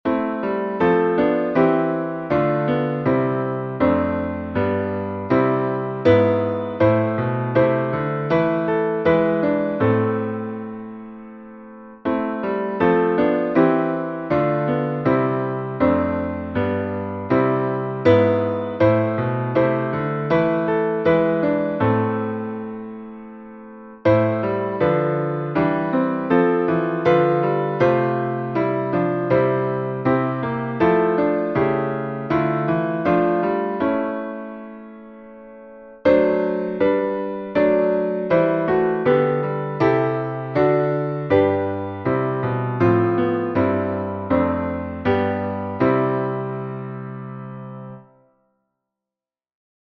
salmo_22B_instrumental.mp3